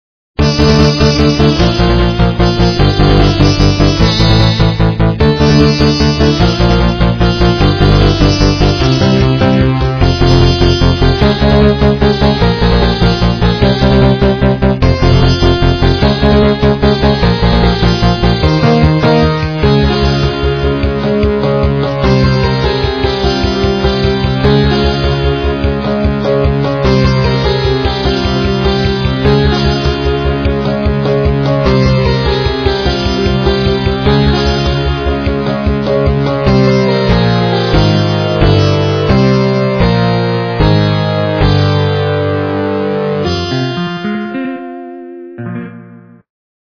- рок, металл
качество понижено и присутствуют гудки
полифоническую мелодию